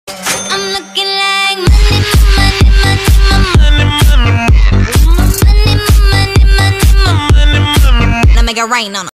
Play, download and share FE59 original sound button!!!!
donate-announcer-money-money-money_KRvmnI9.mp3